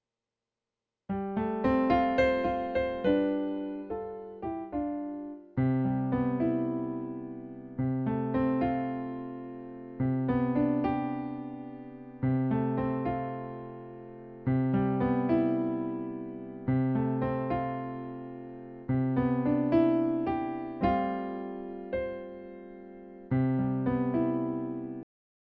PIANO16.wav